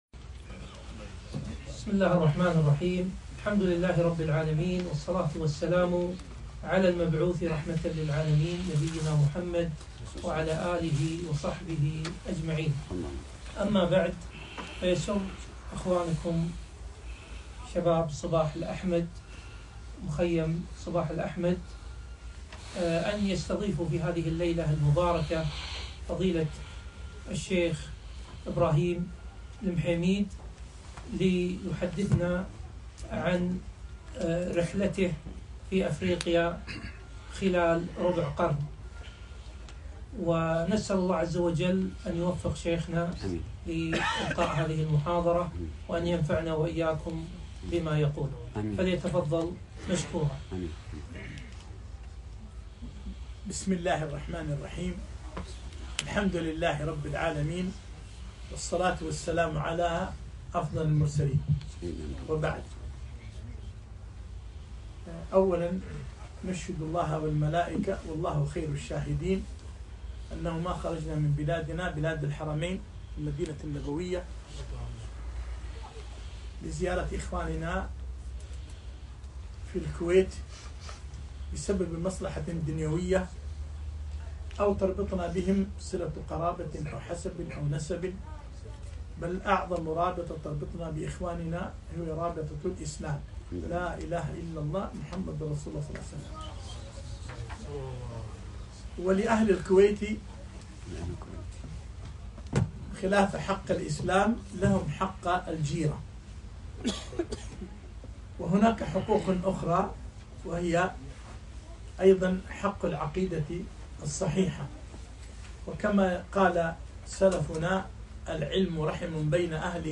محاضرة - مشاهداتي بأفريقيا خلال ربع قرن